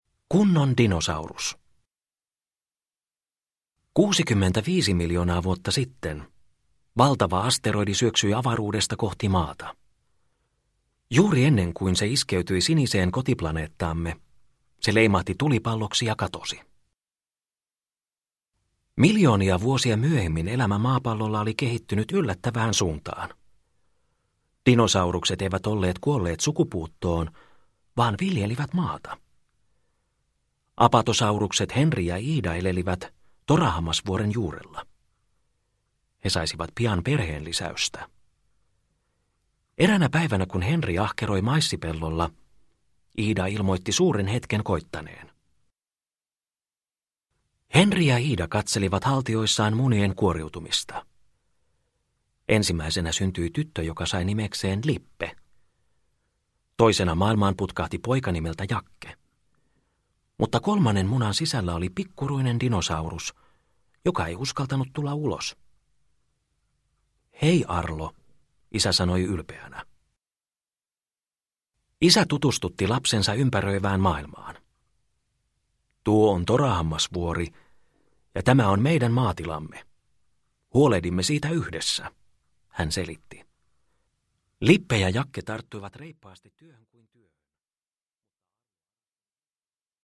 Kunnon dinosaurus – Ljudbok – Laddas ner